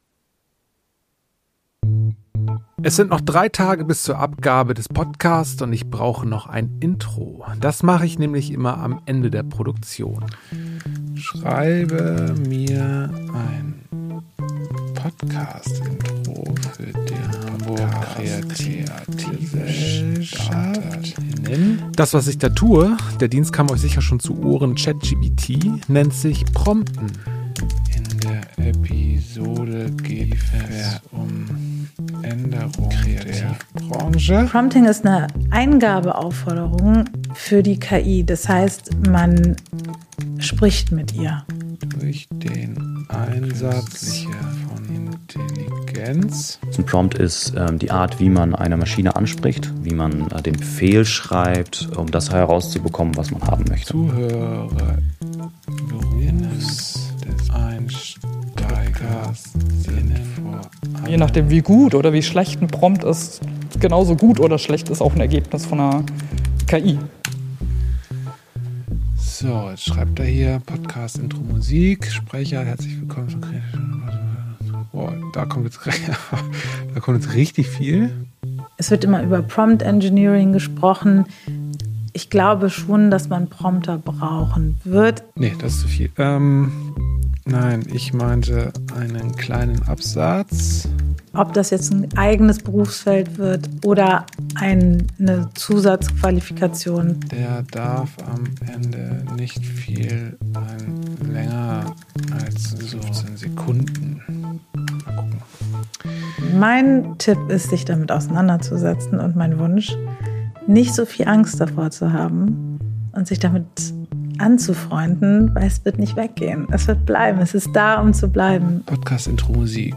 Wir sprechen mit verschiedenen KI-Expert*innen über neu entstehende Berufsbilder und zukünftig relevante Fähigkeiten, wie zum Beispiel das „Prompting“. Außerdem erfahren wir, wo KI bereits in der Kreativwirtschaft eingesetzt wird und besuchen eine Ausstellung zum Thema „KI & Musik“ im Museum für Kunst und Gewerbe.